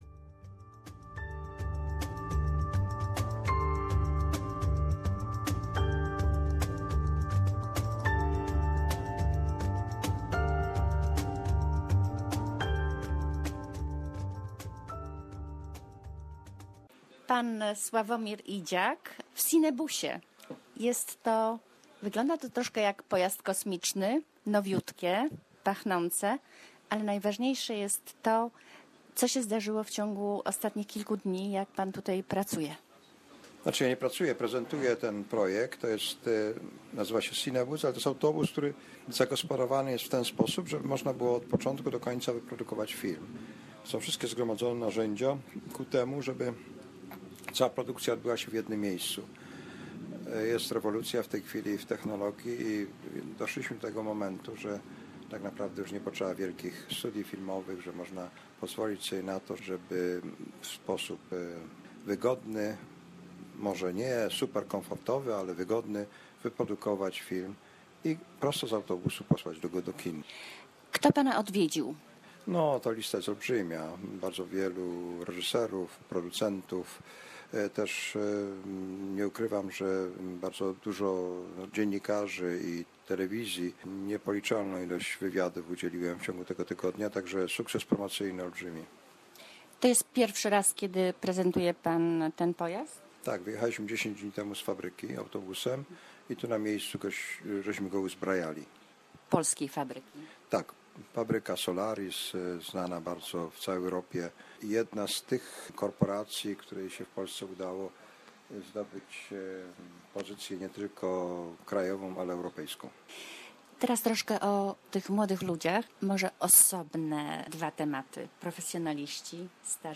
Internationally renowned cinematographer, Slawomir Idziak share his thoughts about the future of the film industry. Interview